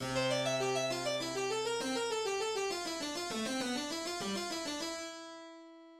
Prélude
Sans ses triples croches, la courbe mélodique est plus lisse[14].